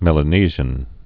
(mĕlə-nēzhən)